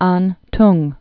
(äntng)